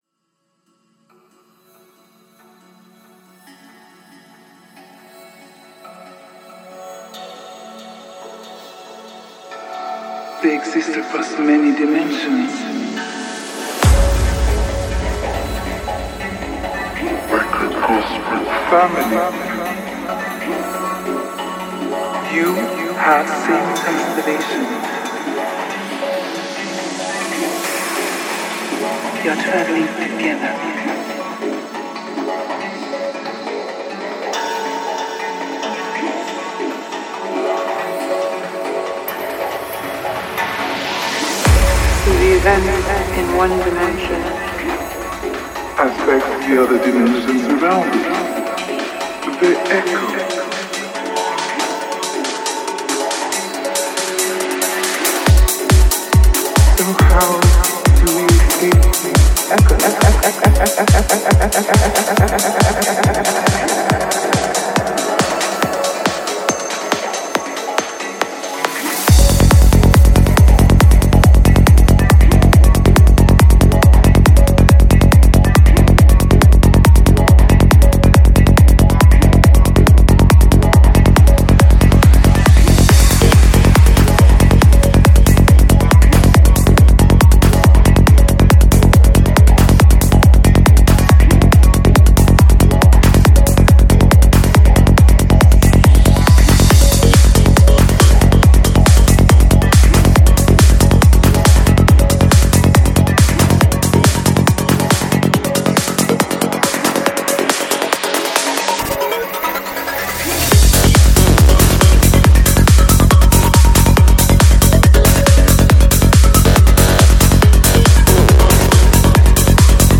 Жанр: Dance
Psy-Trance